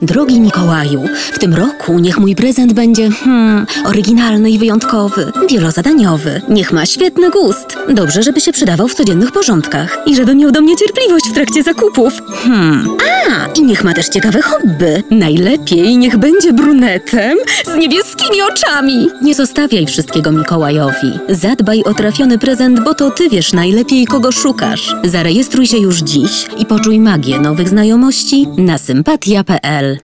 Female 20-30 lat
polski · Turnaround: 48h · Powitanie tel. Reklama Narracja do filmu Audiobook